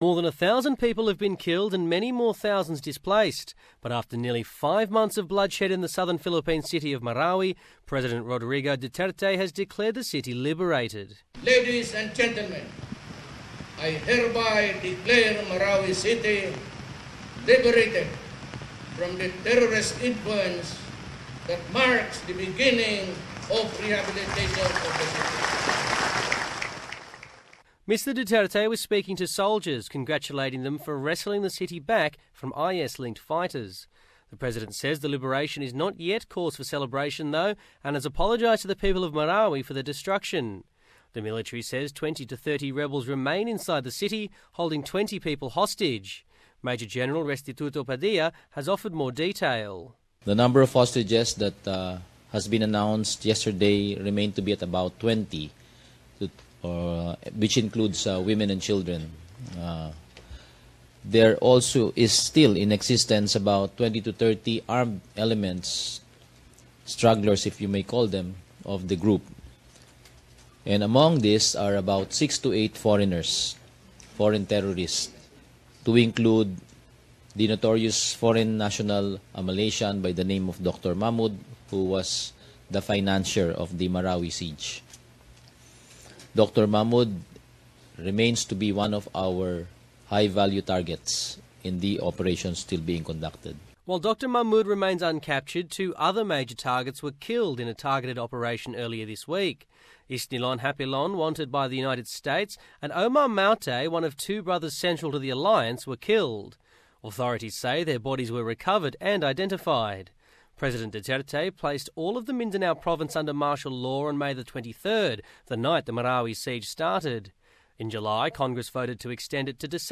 Philippines president Rodrigo Duterte (rod-REE-go doo-TAIR-tay) has declared victory over IS-linked militants in the embattled city of Marawi. The city has been embroiled in nearly five months of fierce fighting.